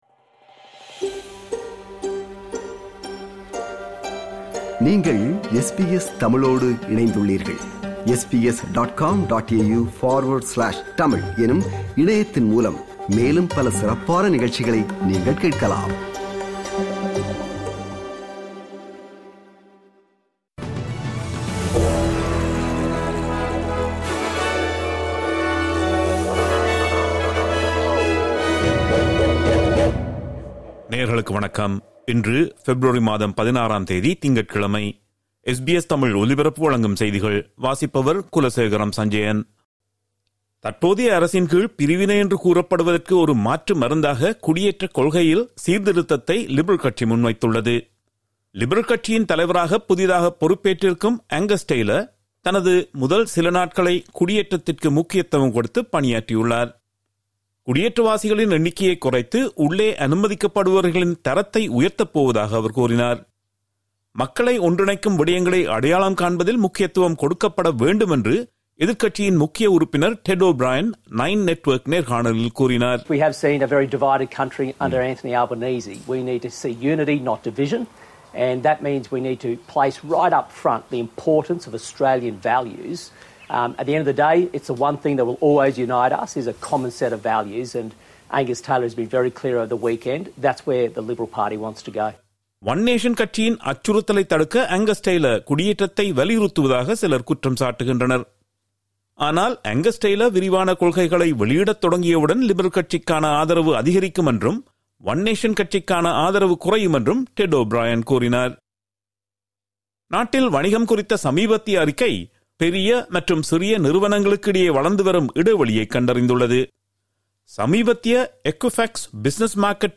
இன்றைய செய்திகள்: 16 பெப்ரவரி 2026 - திங்கட்கிழமை
SBS தமிழ் ஒலிபரப்பின் இன்றைய (திங்கட்கிழமை 16/02/2026) செய்திகள்.